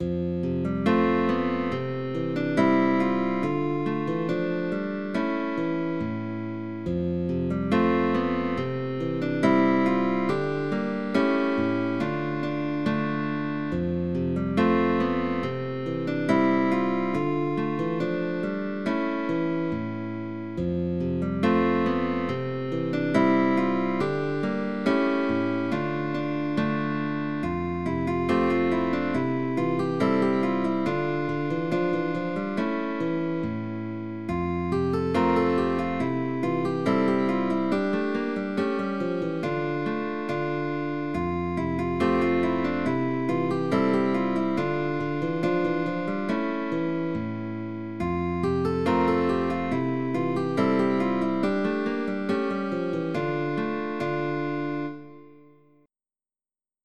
for guitar duo
GUITAR DUO
Baroque